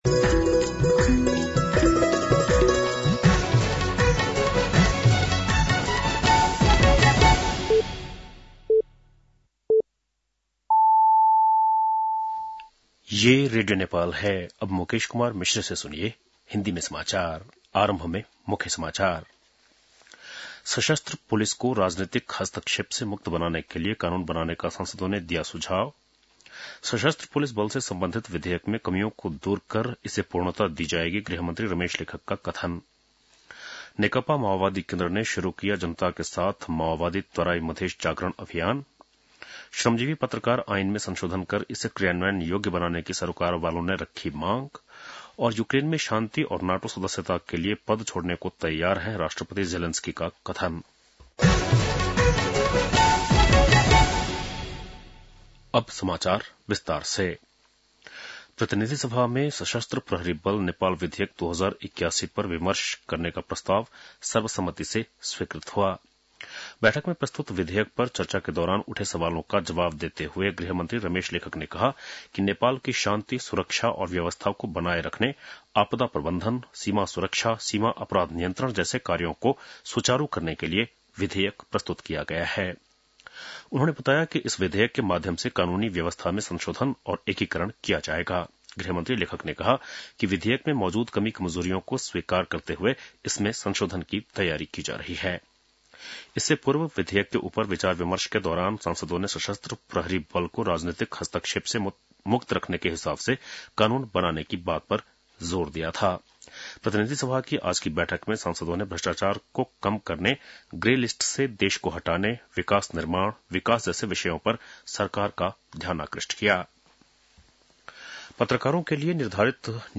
बेलुकी १० बजेको हिन्दी समाचार : १३ फागुन , २०८१